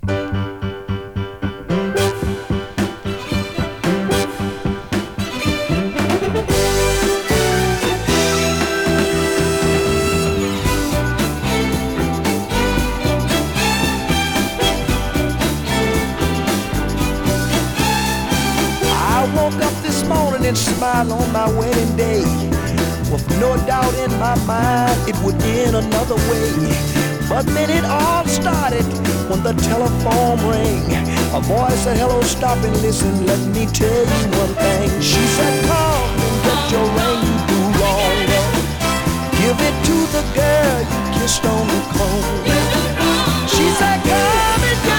内容充実、ノンストップで楽しいノーザンソウルコンピです。
Soul, Funk　UK　12inchレコード　33rpm　Mono